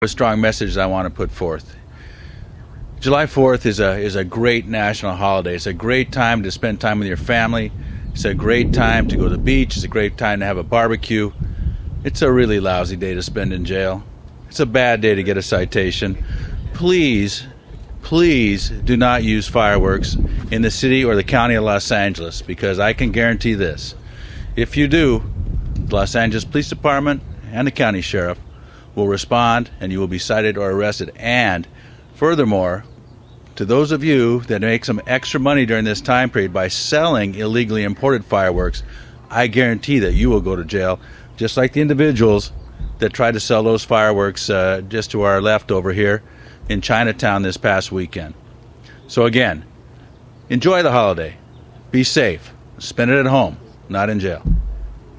July 1, 2025 - Los Angeles Police Chief Charlie Beck joined Mayor Anthony Villaraigosa, Los Angeles County Sheriff Lee Baca, Los Angeles City Fire Chief and Los Angeles County Fire Chief in their 4th of July Anti-Fireworks Press Conference.